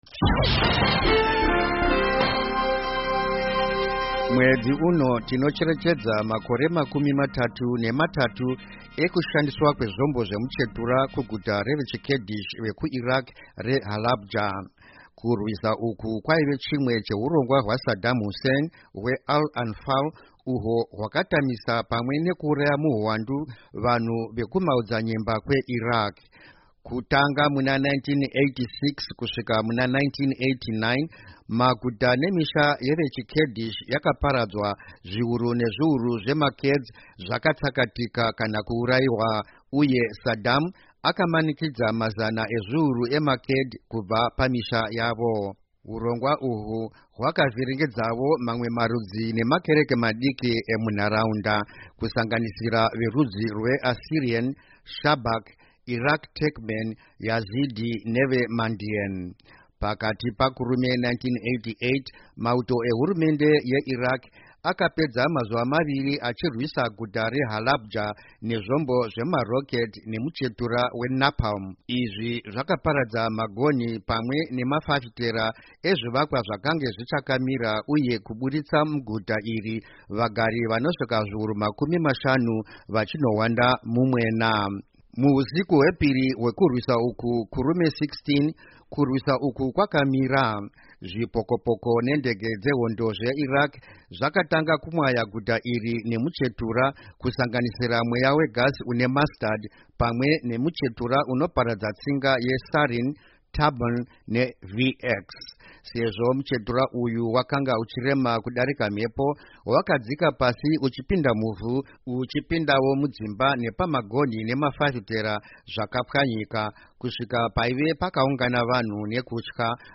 Editorial Remembering Chemical Attack on Halabya, Iraq